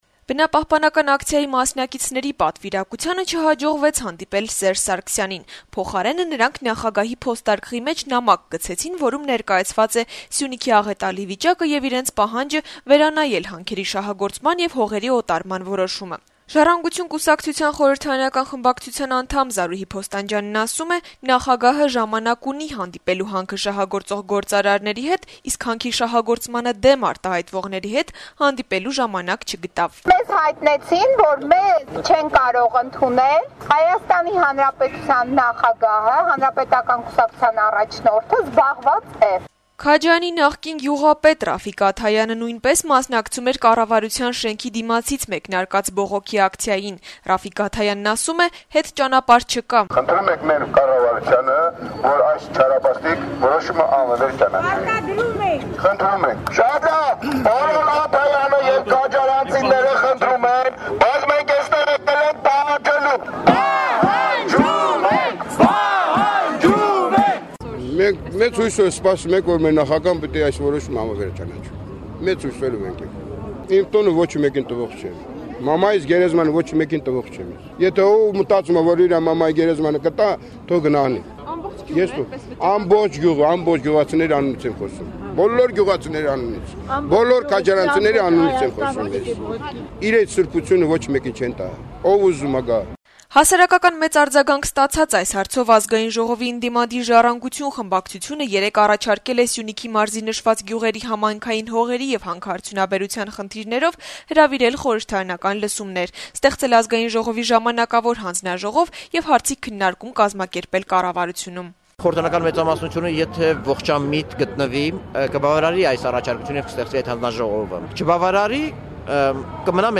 Բողոքի ցույց` ի պաշտպանություն քաջարանցիների